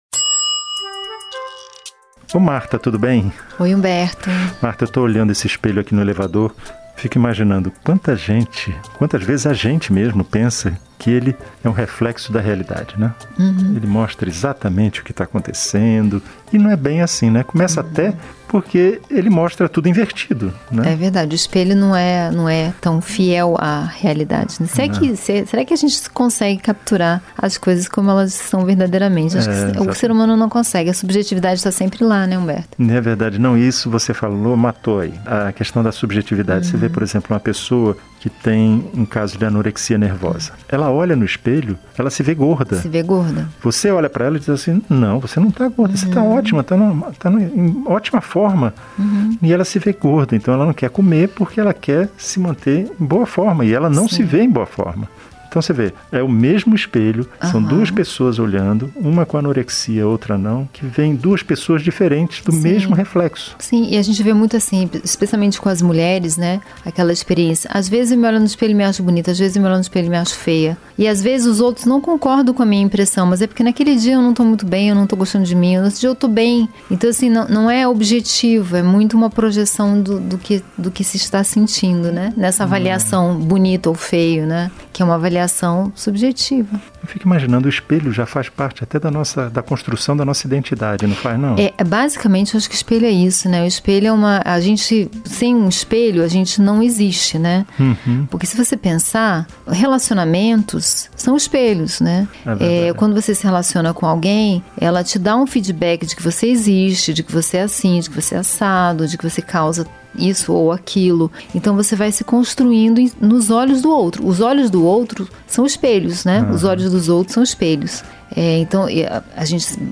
O Conversa de Elevador é um programa de bate-papo sobre temas variados, e que pretende ser ao mesmo tempo leve, breve e divertido, sem deixar de provocar uma reflexão no ouvinte.
Enfim, é uma conversa solta e sem compromisso, marcada pelas experiências do dia-a-dia e pela convivência em um mundo que às vezes parece tão rápido e tão cheio de subidas e descidas quanto um elevador.